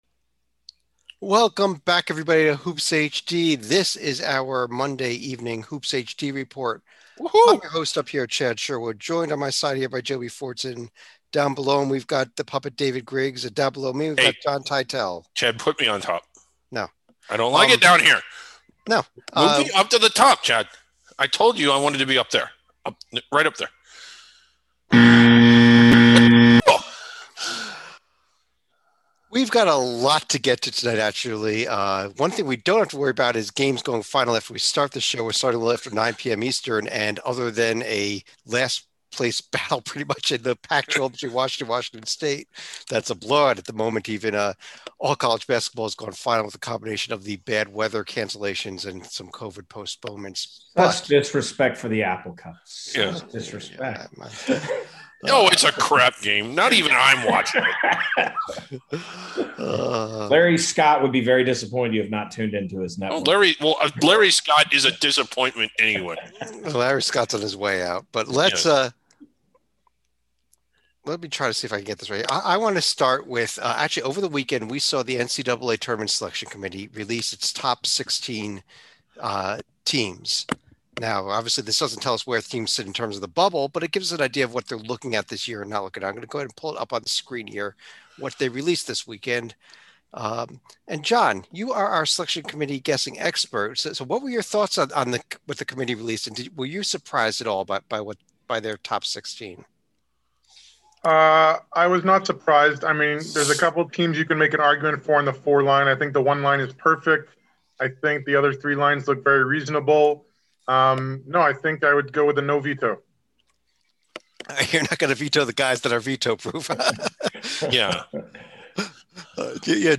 the panel look back at another busy week in college basketball.